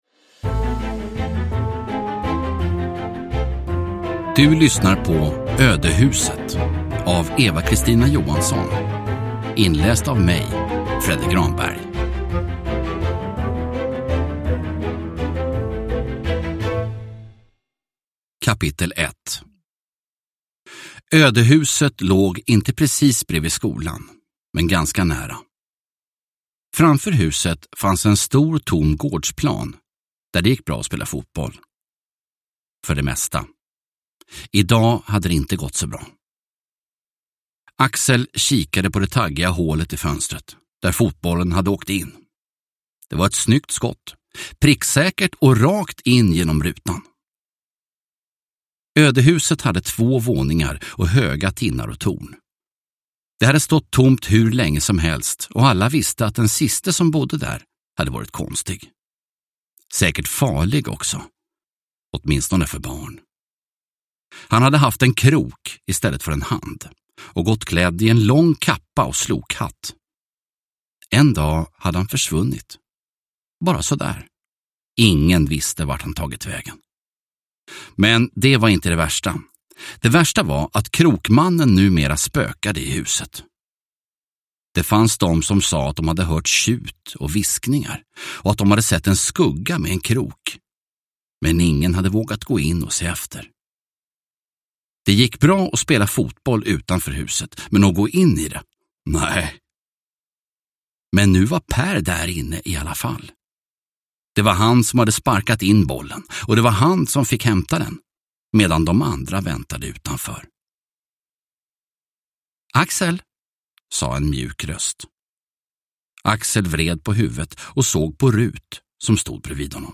Ödehuset – Ljudbok – Laddas ner